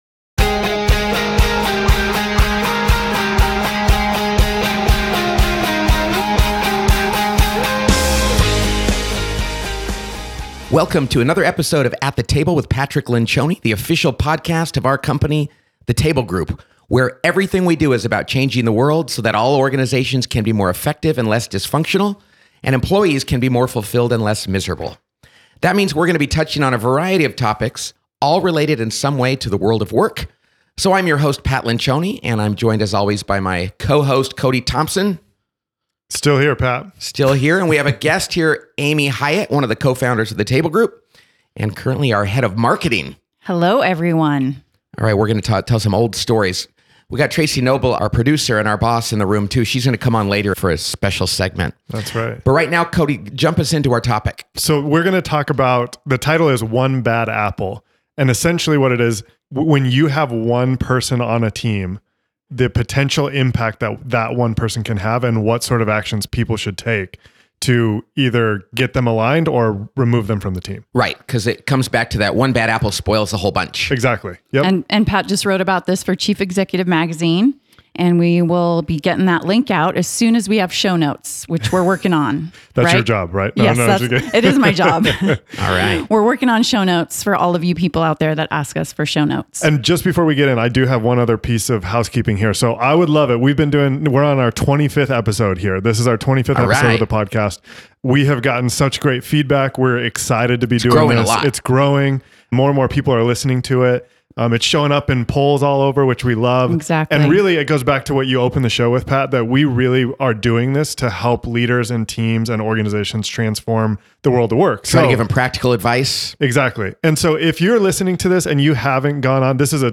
Can one bad apple really spoil the whole bunch? Pat and the team discuss the impact of even one team member who doesn't fit.